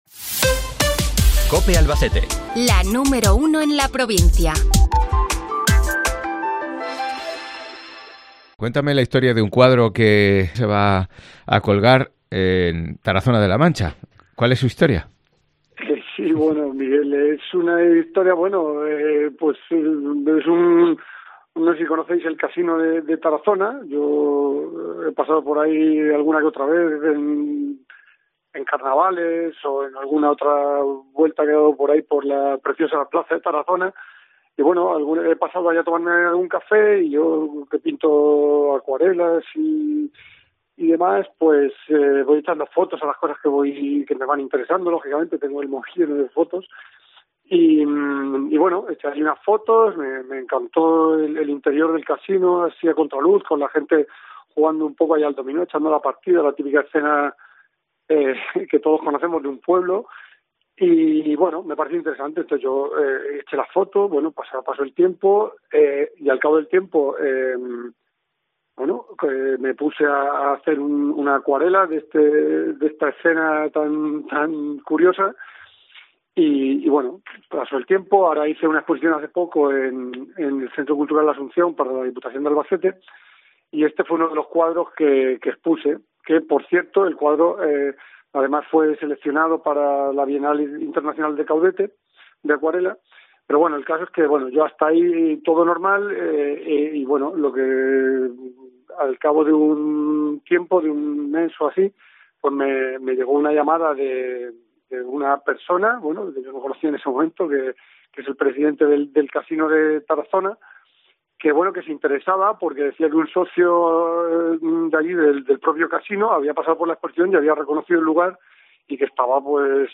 En conversación con COPE Albacete